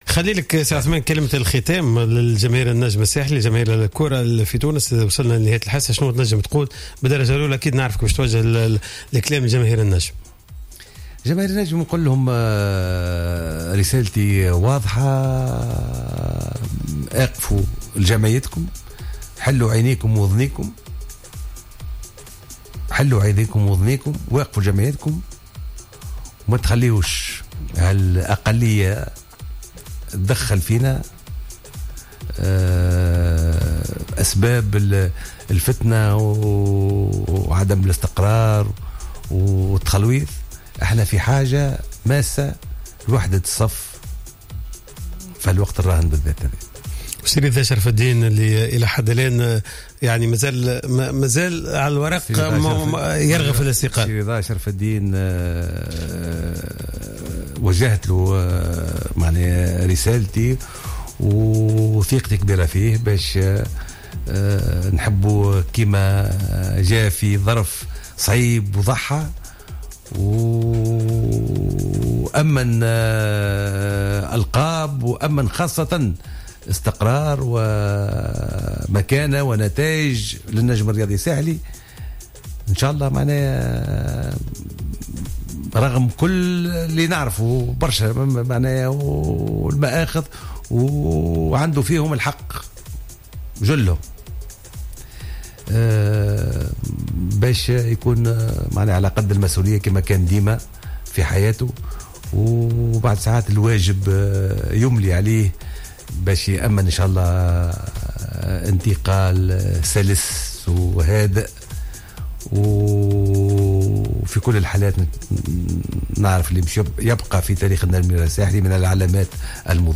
Invité de l'émission "Goal" ce vendredi 14 octobre 2016